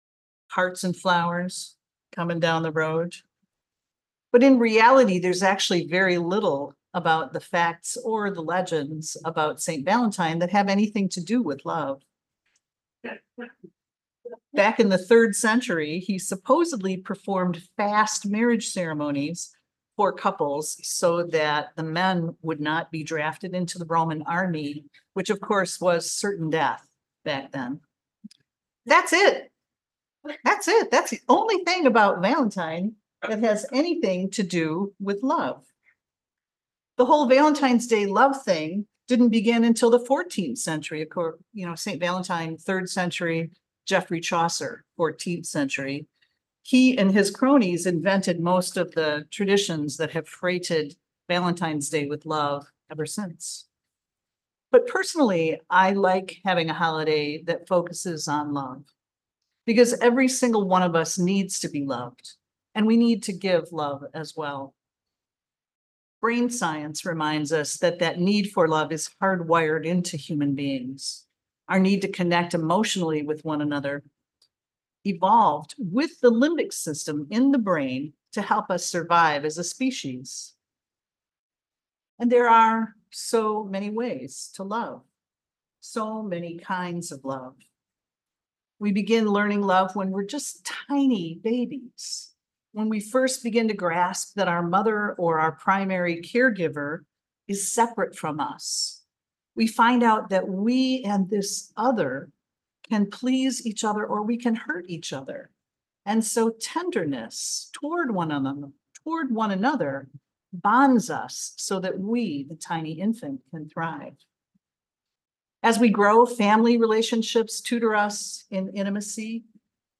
[We apologize for tech/recording issues from this service.